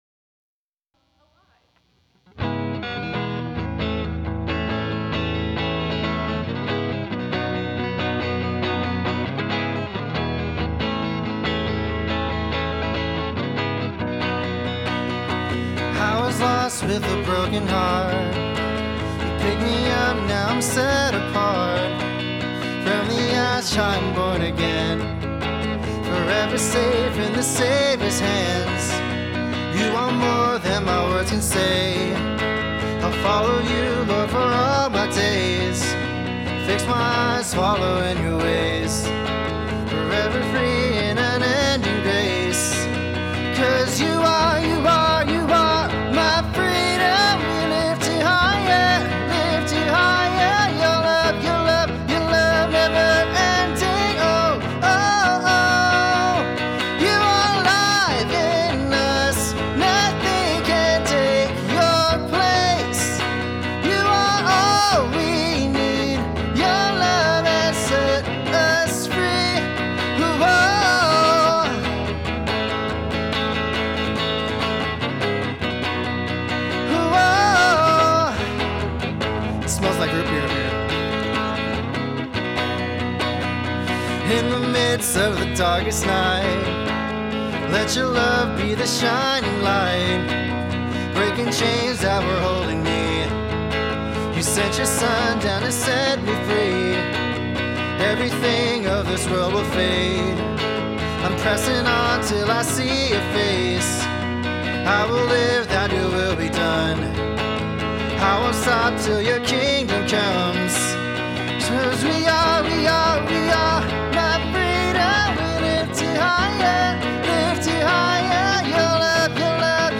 Music form Summer Camp